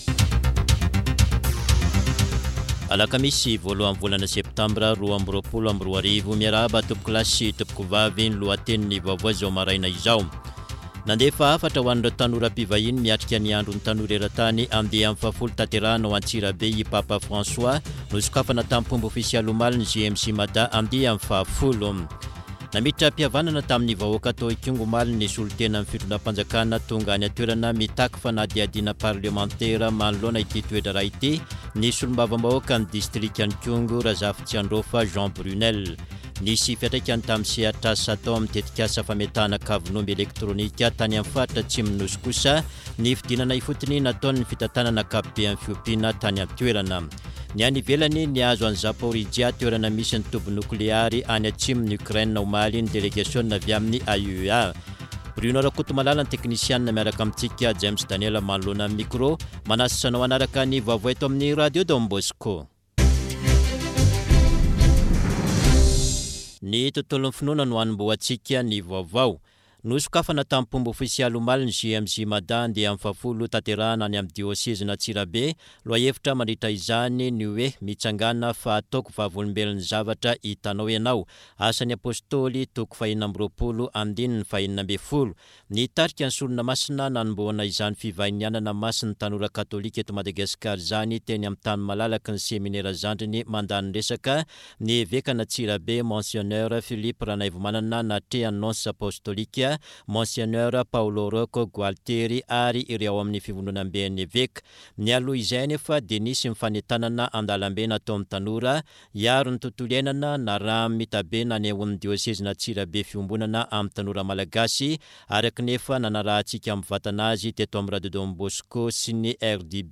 [Vaovao maraina] Alakamisy 01 septambra 2022